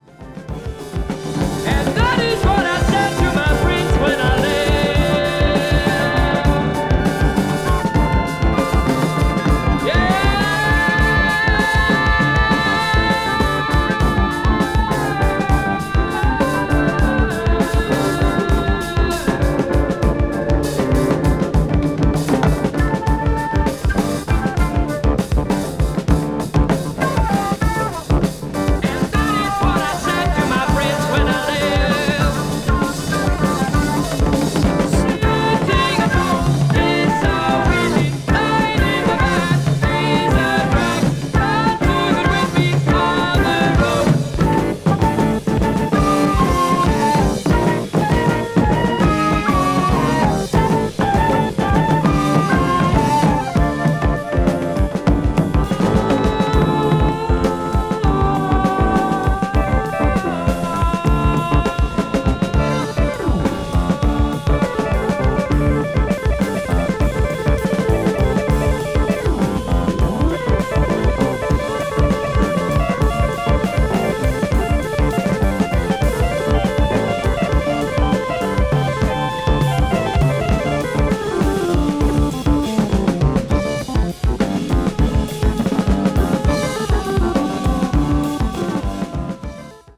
A Kraut-jazz-rock group